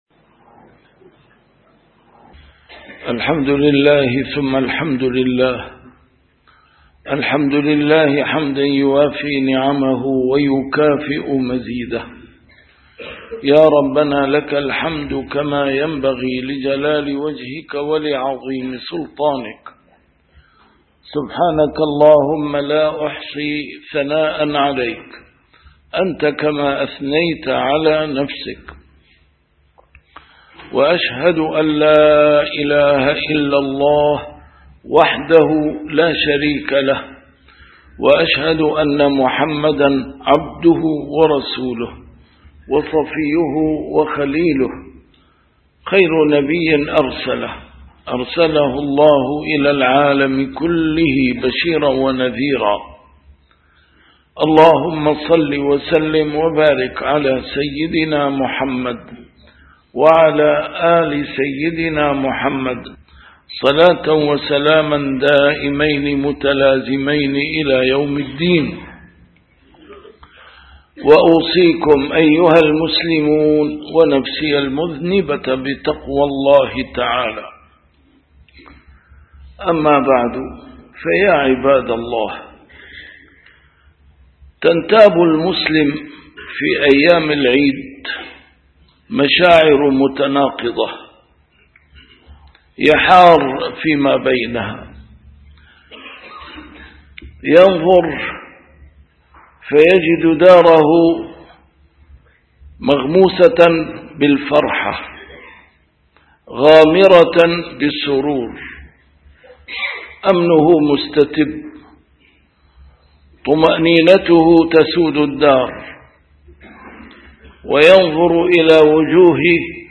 A MARTYR SCHOLAR: IMAM MUHAMMAD SAEED RAMADAN AL-BOUTI - الخطب - روح الأسباب المادية في حياة المسلمين هو صدق الالتجاء إلى الله